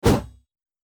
attack_both_wp_1.mp3